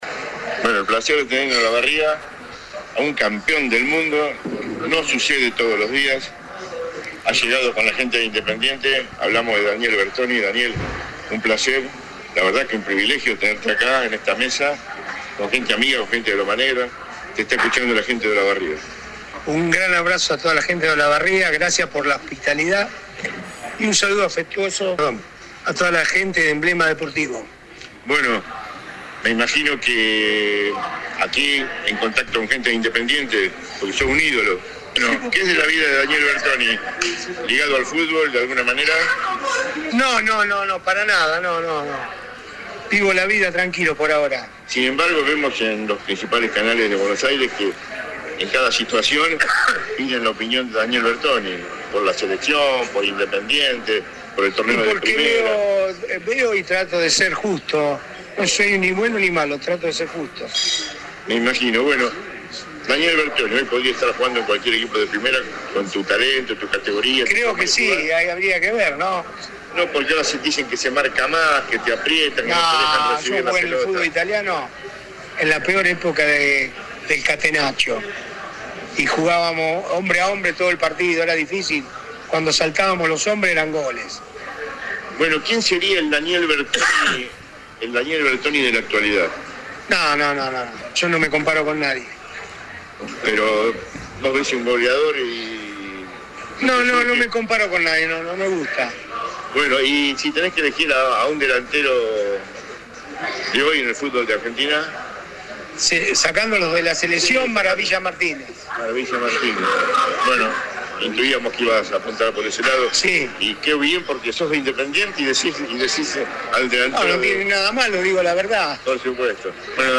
AUDIO CON EL SALUDO Y OTRAS PALABRAS DE DANIEL BERTONI Audio Daniel Bertoni ( CLIQUEAR PARA ESCUCHAR )